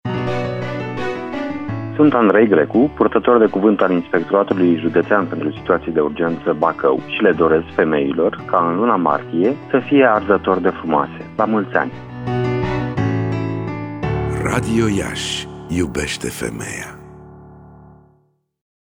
De astăzi, timp de o săptămână, pe frecvenţele de 1053 Khz, 90,8, 94,5 şi 96,3 Mghz, vor fi difuzate următoarele spoturi: